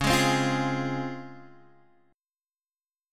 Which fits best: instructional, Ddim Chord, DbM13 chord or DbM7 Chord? DbM7 Chord